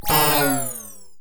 snd_power_down.wav